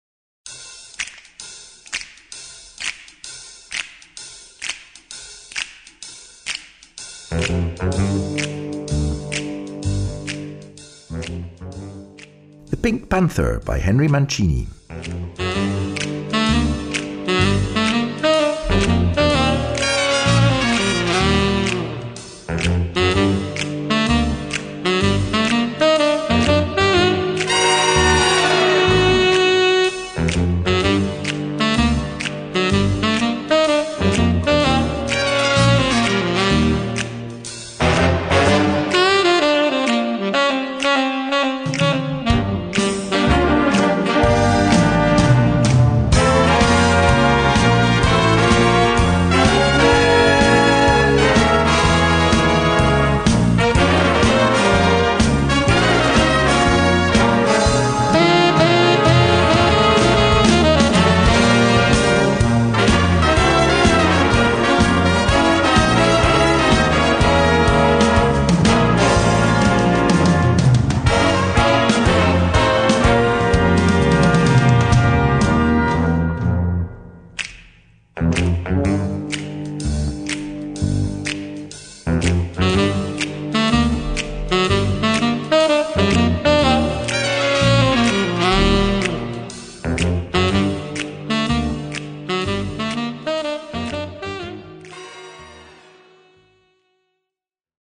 Gattung: Solo für Posaune oder Tenor-Saxophon
Besetzung: Blasorchester